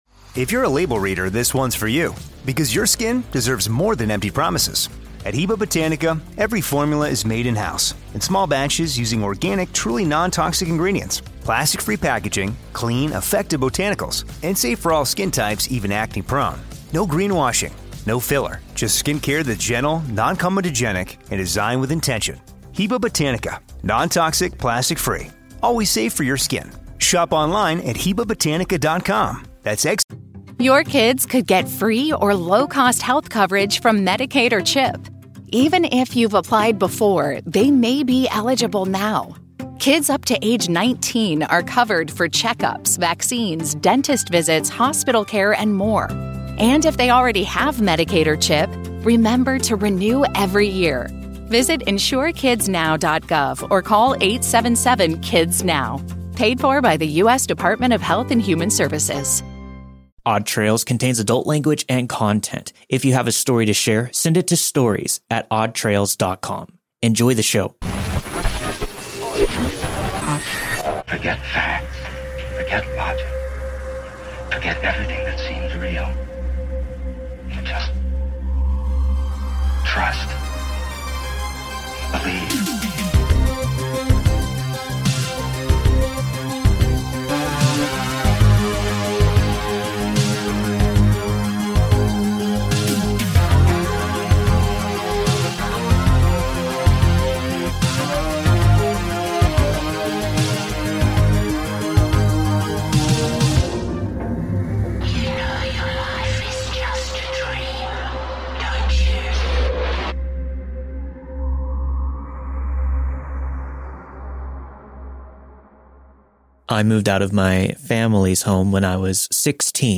All stories were narrated and produced with the permission of their respective authors.